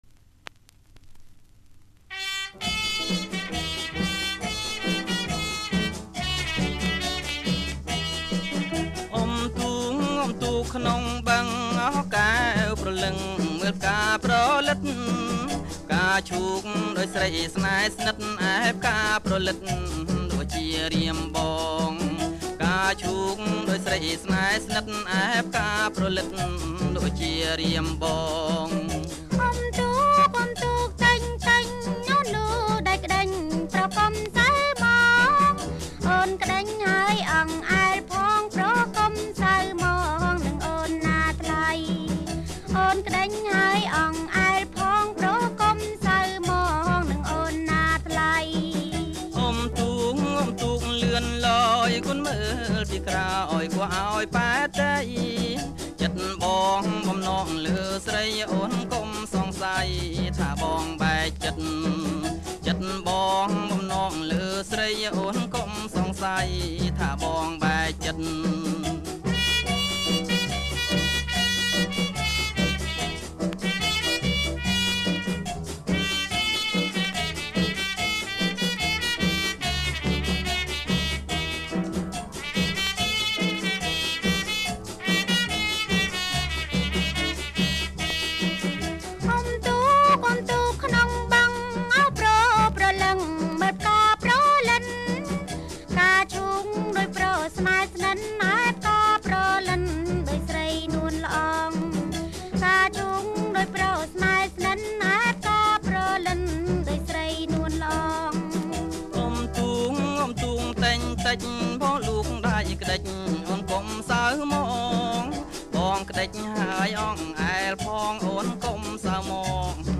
ចង្វាក់ Ramvong